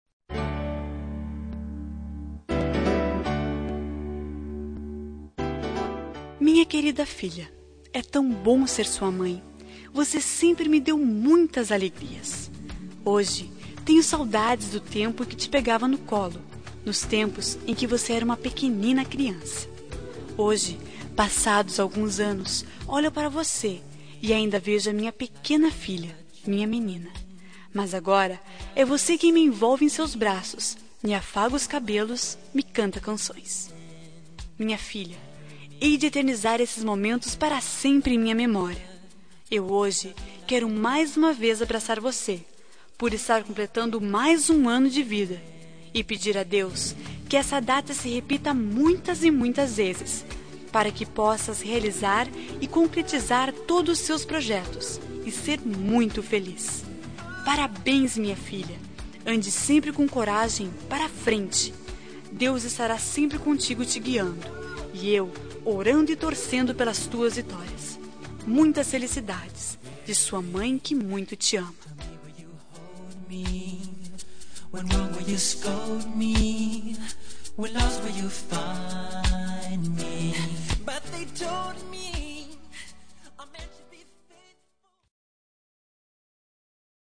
Telemensagem de Aniversário de Filha – Voz Feminina – Cód: 1774